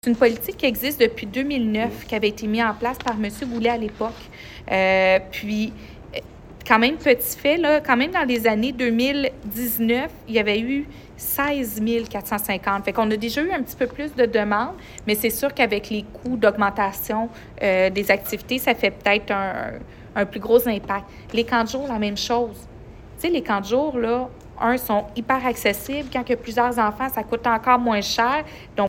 Nouvelles
C’est ce qu’a indiqué la mairesse de Granby, Julie Bourdon, en mêlée de presse à la suite de la séance du conseil municipal, qui avait lieu lundi soir.